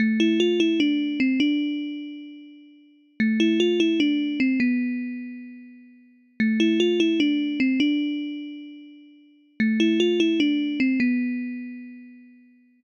Tag: 150 bpm Hip Hop Loops Piano Loops 2.16 MB wav Key : Unknown